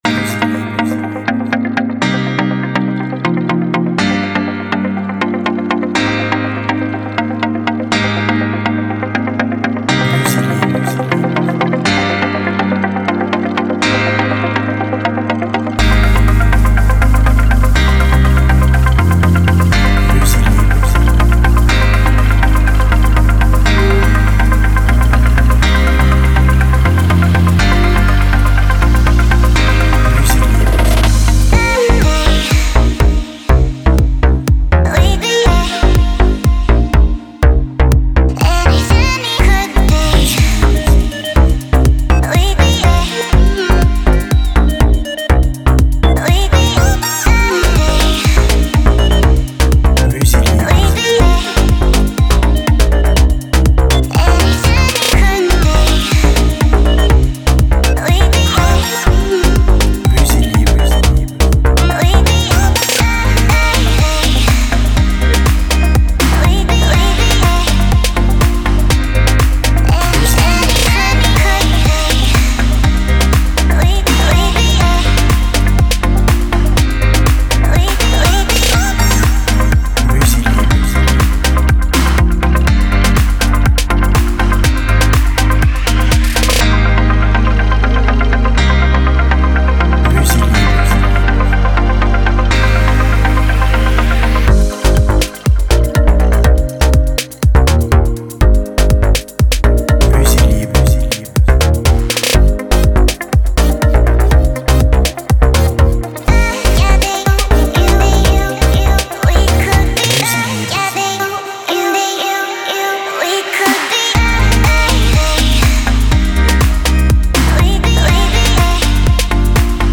Musique libre de droits EDM qui file la pêche!
BPM Rapide